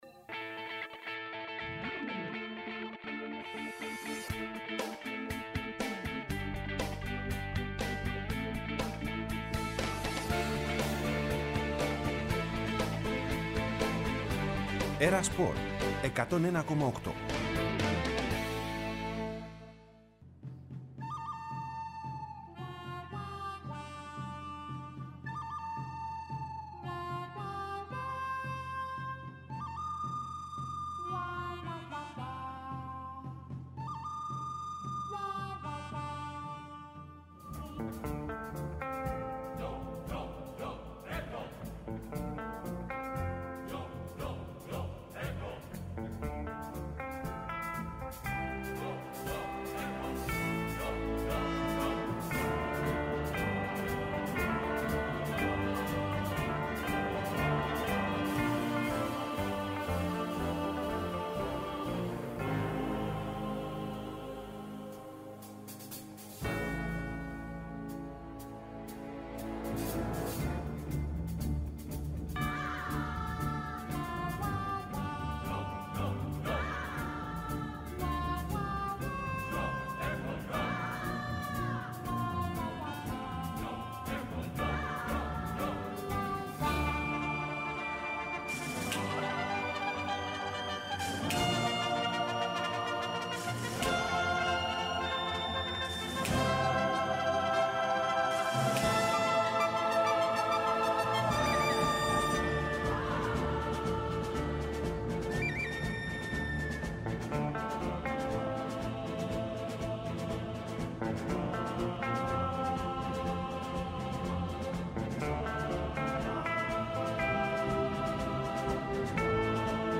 Ενημέρωση από τους ρεπόρτερ του Ολυμπιακού, του Παναθηναϊκού, της ΑΕΚ, του ΠΑΟΚ και του Άρη.